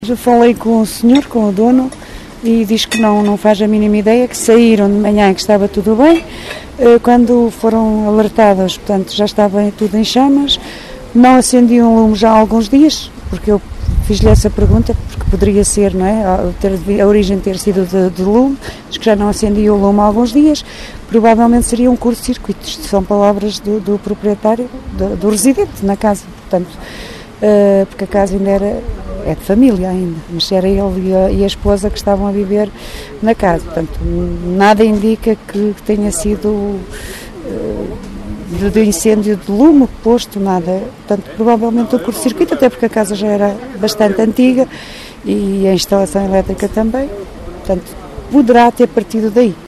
Em Junho, e, por isso, sem lareira acesa, a causa mais provável, ainda que sem confirmações oficiais, é um curto-circuito, como conta Delfina Sarmento, a presidente da Junta de Freguesia de Vinhas.